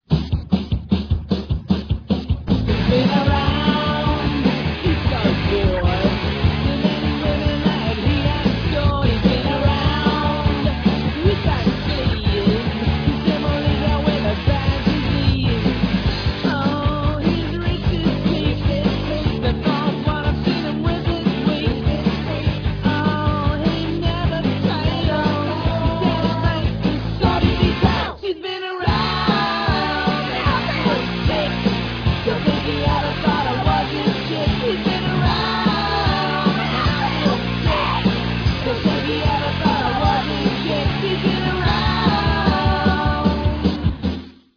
Vendredi 17 octobre à 21h – Concert garage-punk